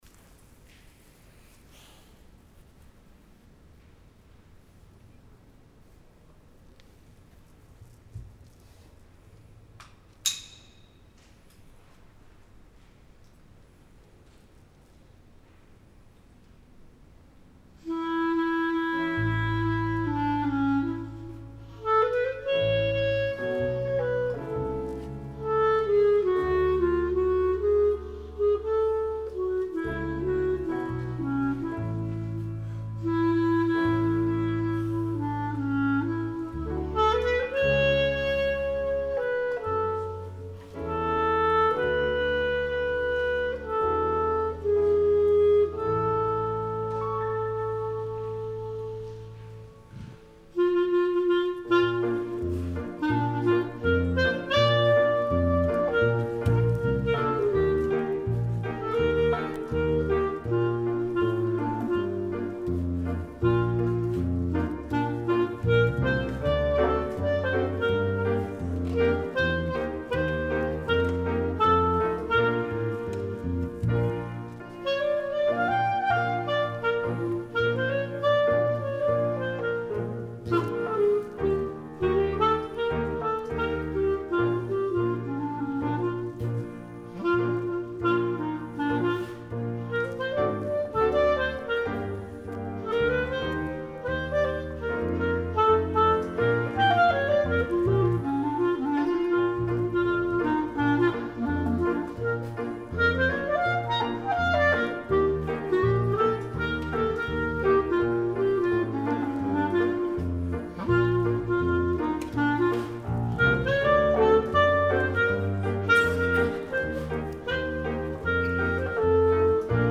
Thank you for joining us for this weekend's special Celebrate Freedom worship service!